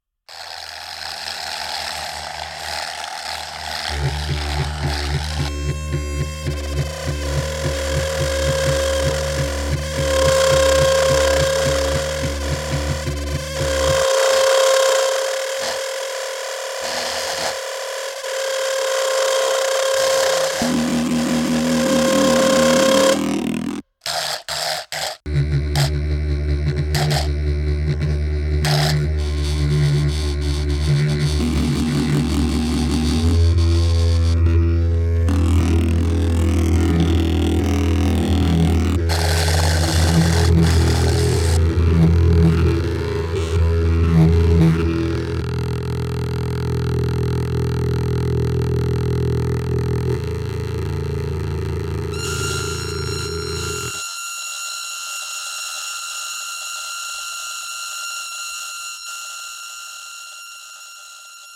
alto saxophone with splitter
ZKM Karlsruhe 2012